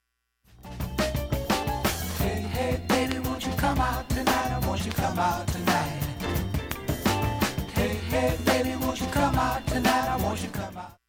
ほか単発のかすかなプツが３箇所